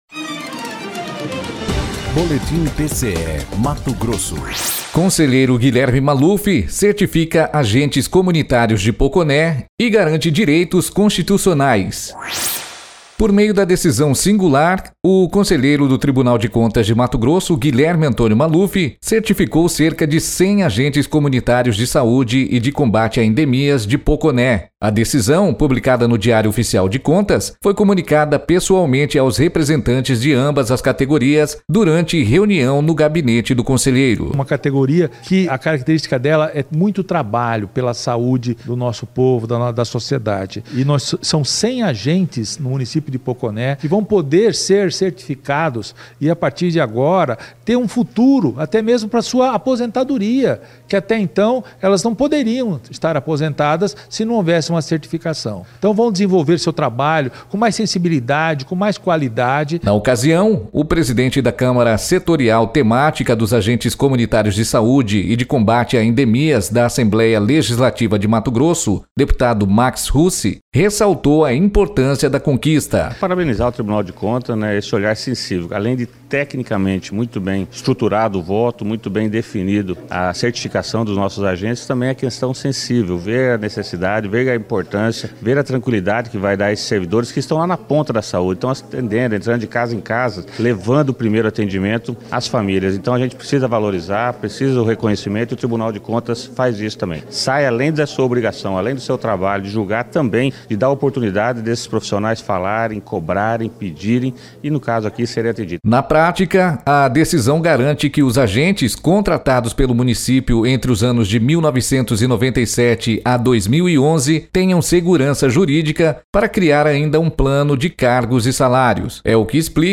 Sonora: Guilherme Antonio Maluf – conselheiro do TCE-MT
Sonora: Max Russi – deputado e presidente da Câmara Setorial Temática dos Agentes Comunitários de Saúde e de Combate a Endemias da AL-MT